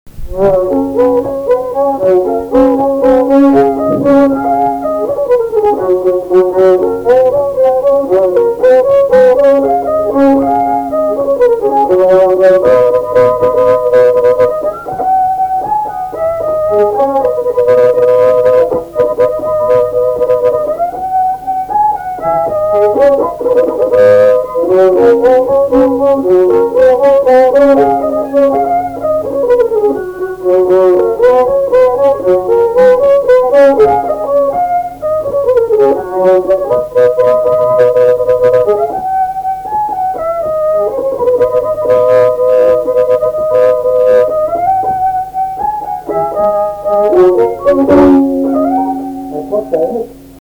Mazurka
šokis